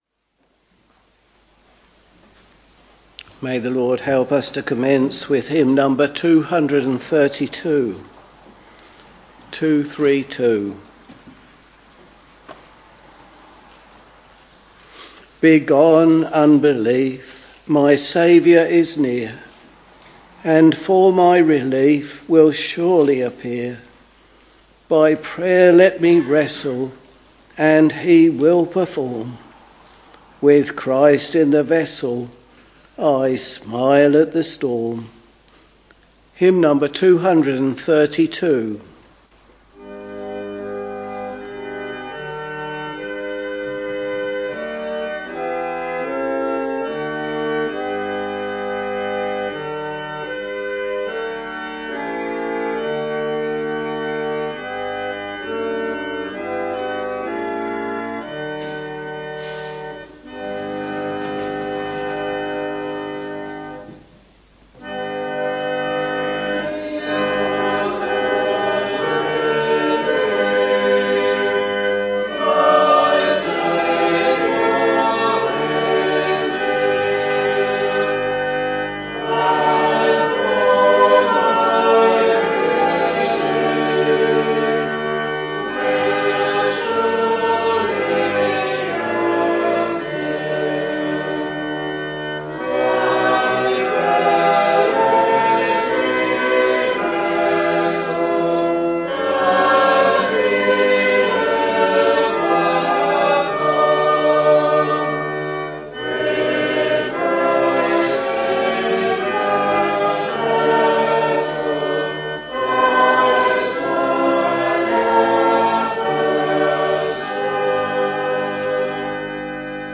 Week Evening Service Preacher